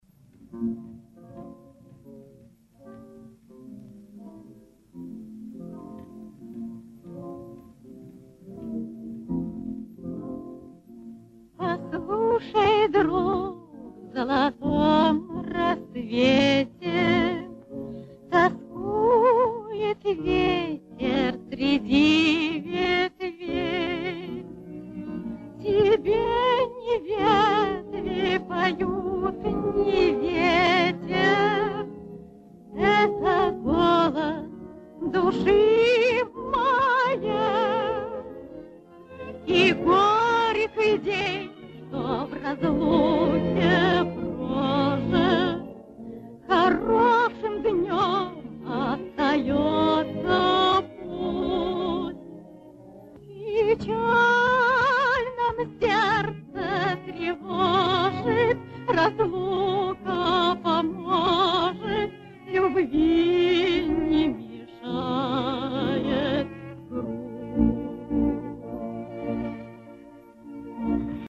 Первая песня (фрагмент из фильма)
В интернете есть информация, что песни исполняет эта актриса